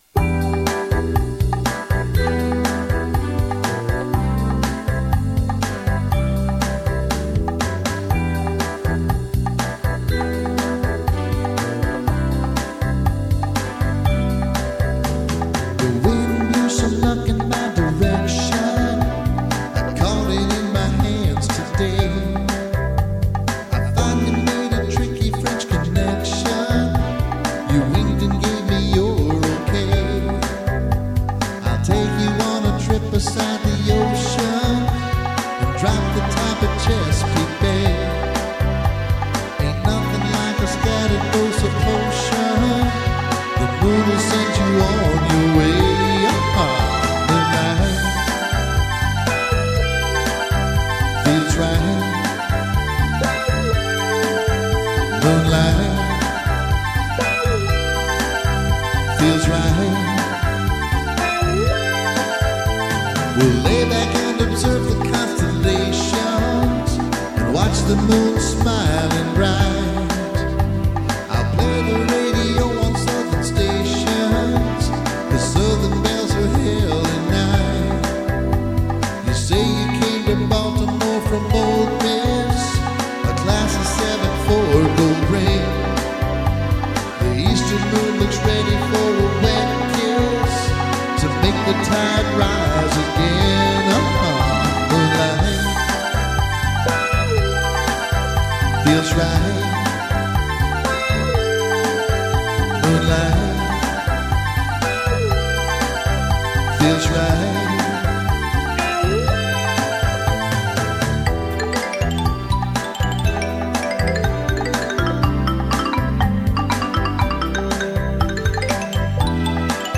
LIVE RECORDINGS DURING ACTUAL SHOW (full songs):